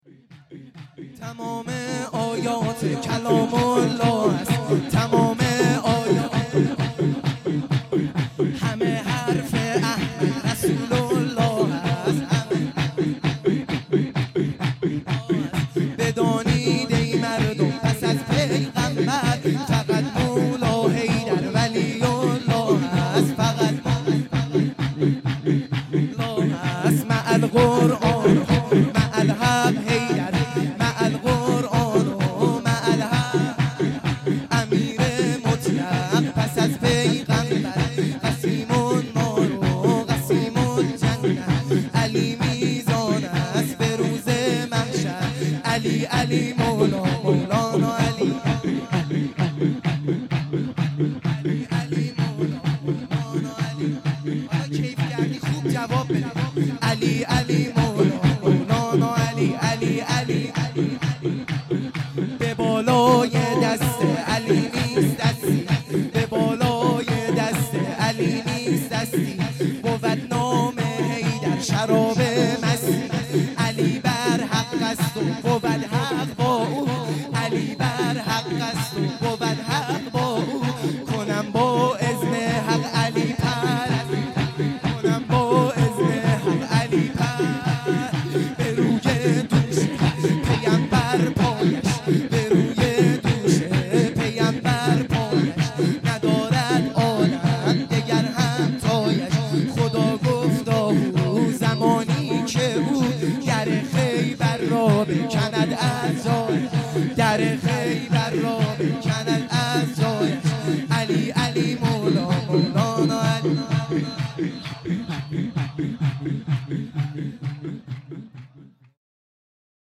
سرود | تمام آیات کلام الله است